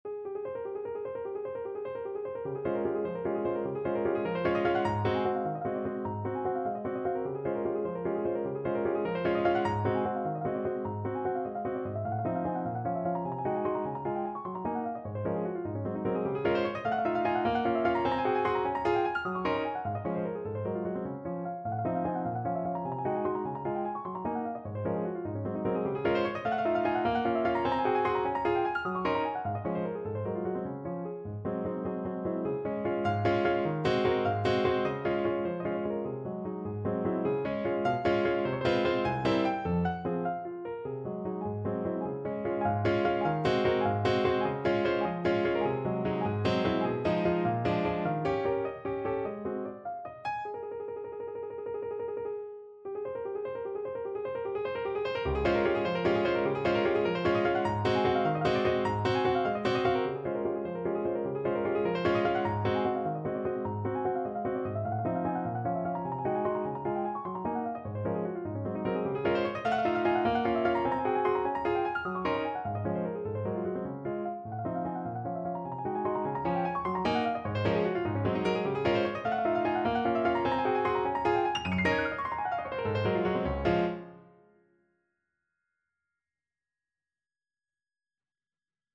Free Sheet music for Piano
No parts available for this pieces as it is for solo piano.
3/4 (View more 3/4 Music)
Molto vivace ( .=100)
Db major (Sounding Pitch) (View more Db major Music for Piano )